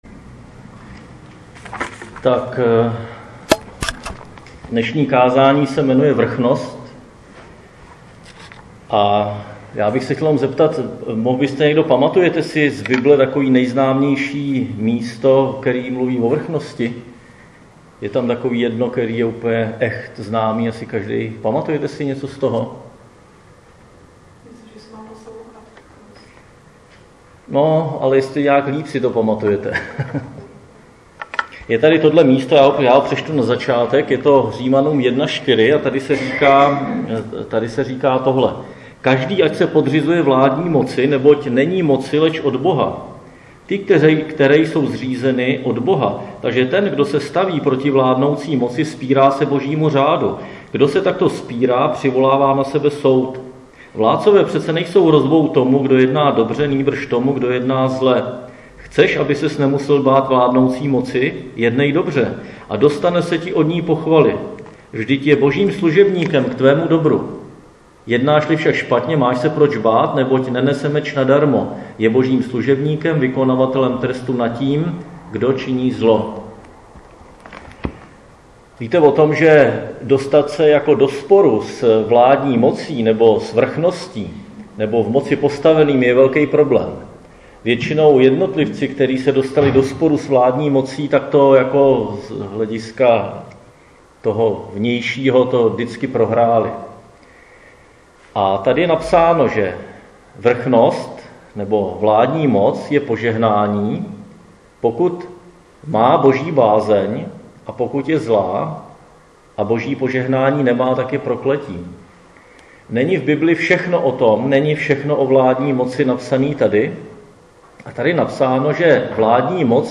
Křesťanské společenství Jičín - Kázání